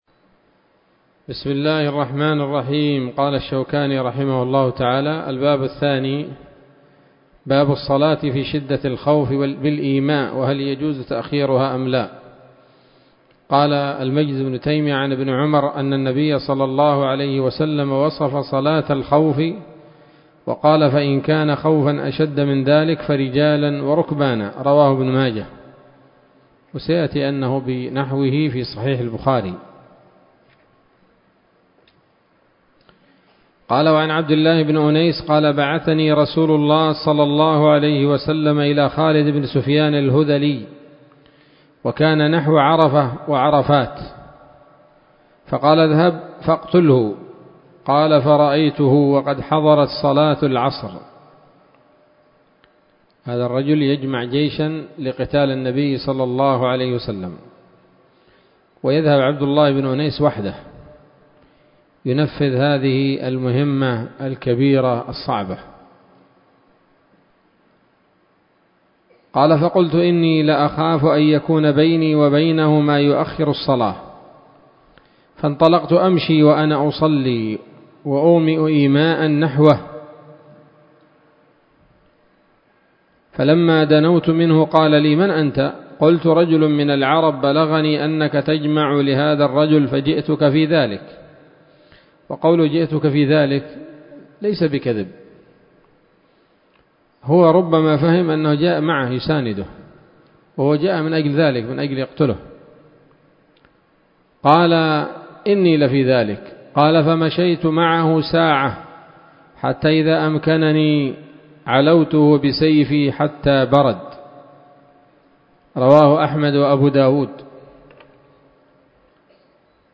الدرس السادس من ‌‌‌‌كتاب صلاة الخوف من نيل الأوطار